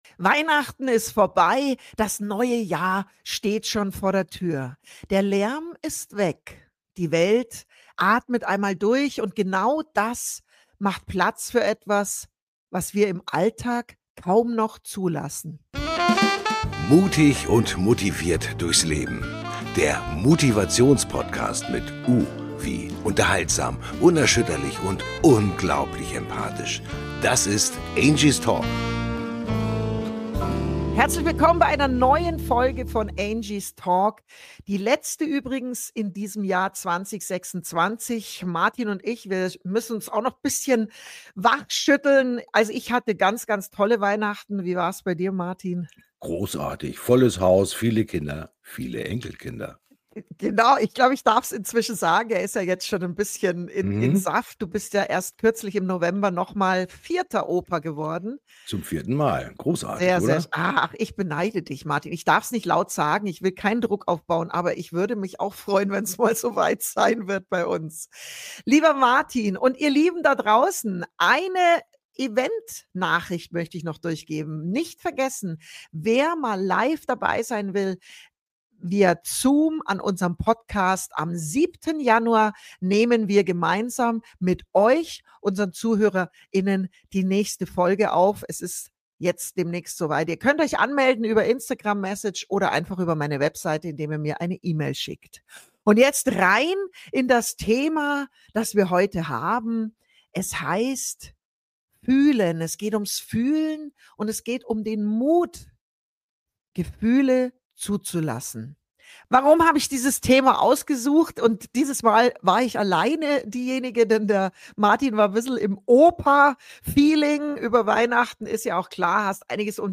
Eine ruhige, tiefgehende Folge – für mehr Mut, Echtheit und innere Klarheit.